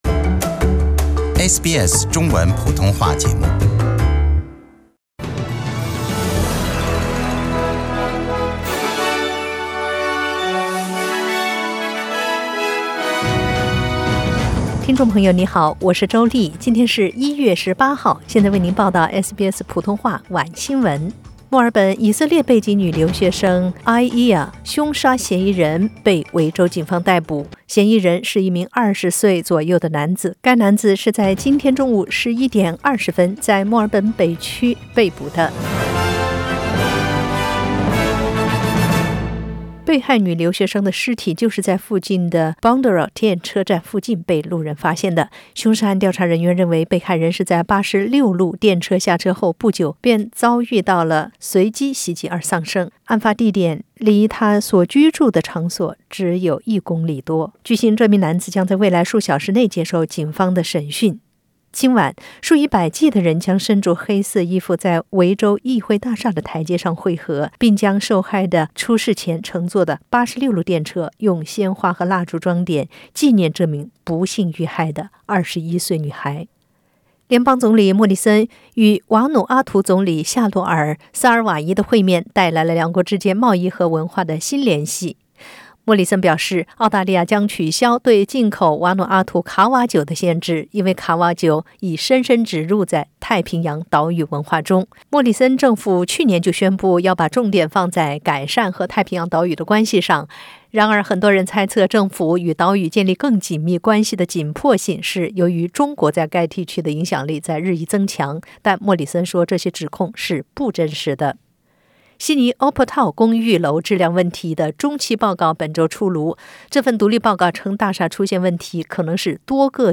SBS 晚新闻 （1月18日）